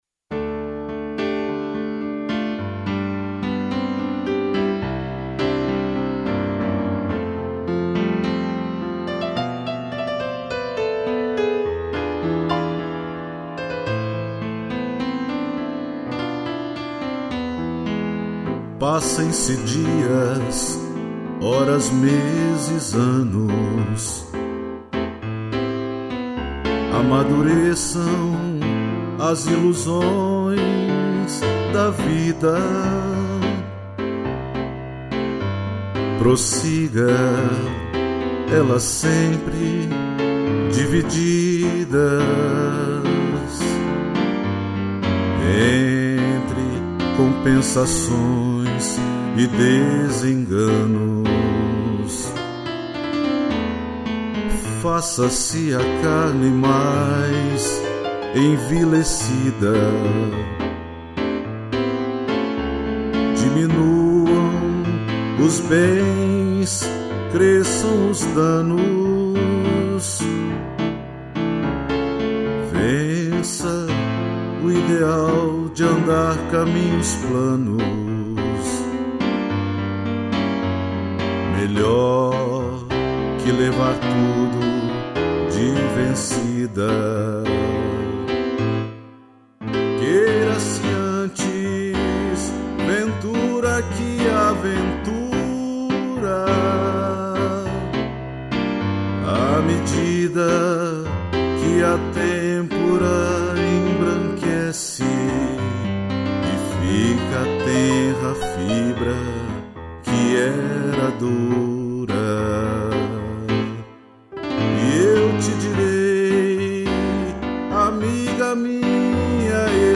voz
piano